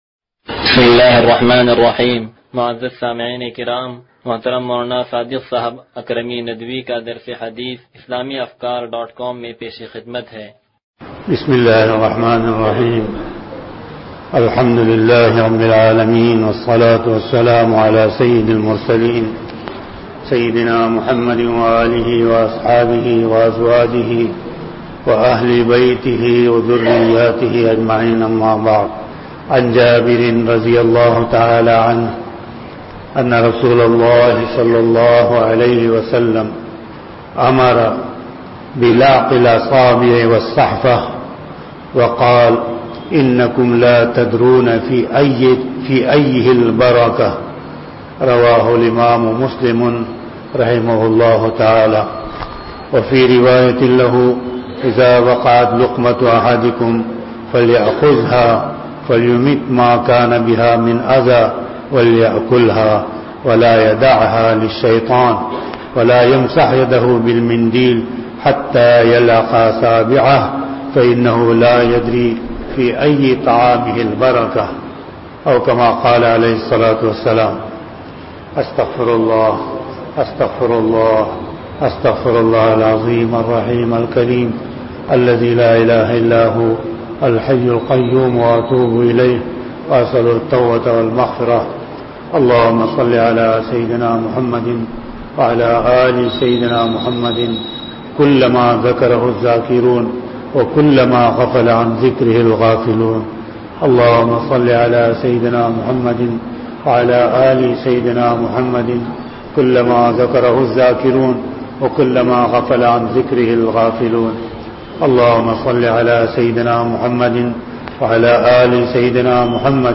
درس حدیث نمبر 0172
سلطانی مسجد